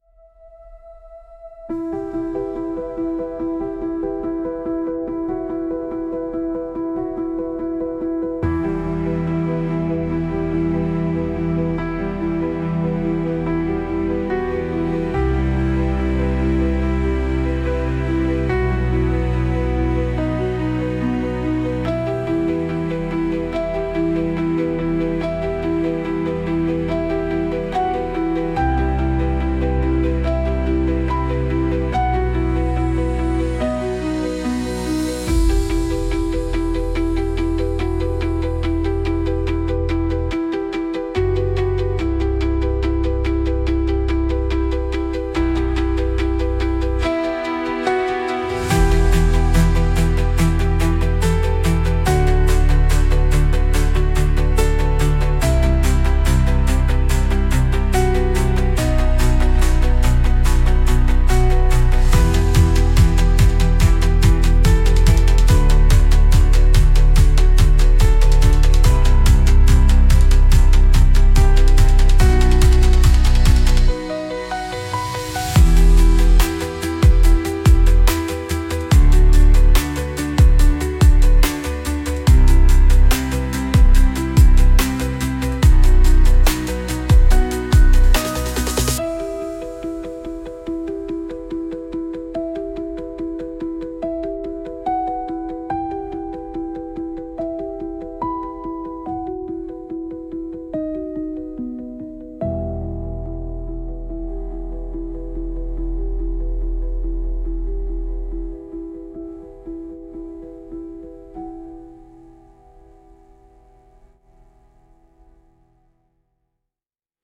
Instrumental- Echoes Under Ash - 1.57 Mins